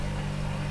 fridgeLoop.mp3